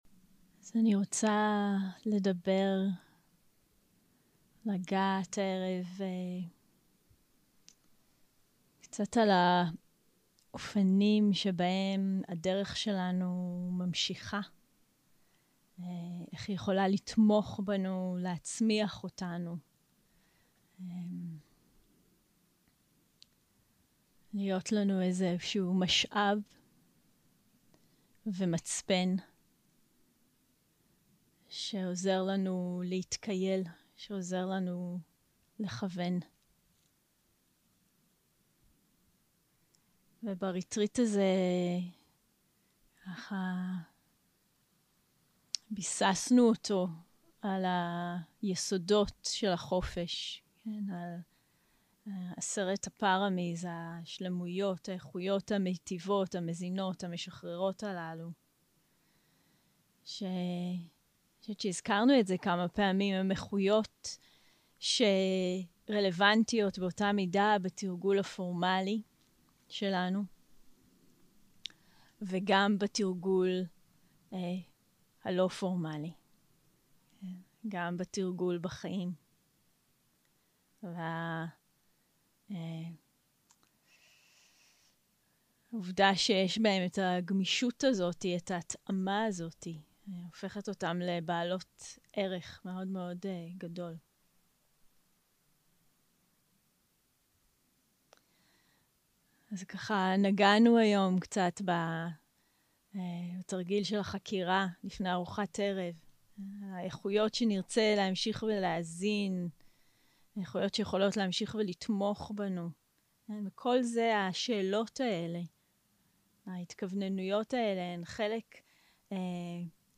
יום 6 - הקלטה 16 - ערב - שיחת דהרמה - התהוות מותנית של סילה.
0:00 0:00 סוג ההקלטה: Dharma type: Dharma Talks שפת ההקלטה: Dharma talk language: Hebrew